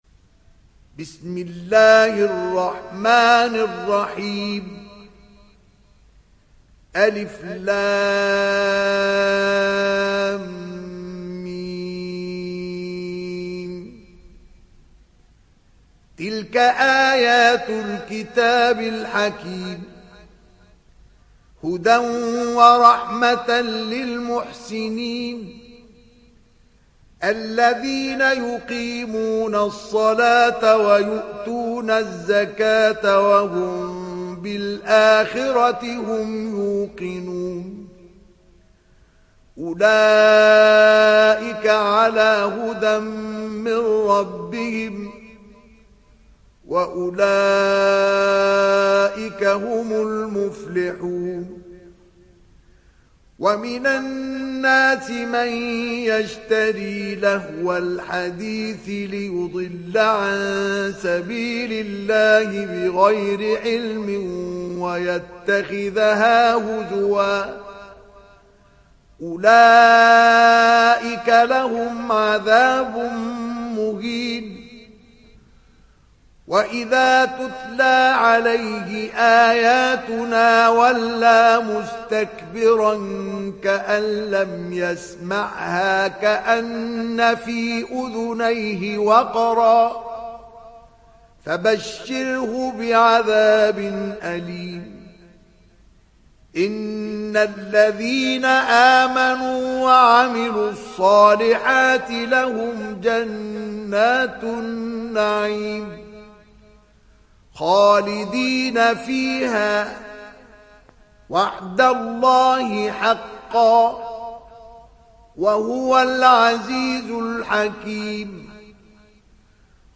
Surat Luqman Download mp3 Mustafa Ismail Riwayat Hafs dari Asim, Download Quran dan mendengarkan mp3 tautan langsung penuh